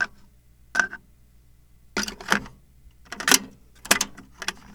Index of /90_sSampleCDs/E-MU Producer Series Vol. 3 – Hollywood Sound Effects/Miscellaneous/Cassette Door
CASSETTE 01R.wav